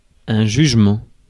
Ääntäminen
France: IPA: /ʒyʒ.mɑ̃/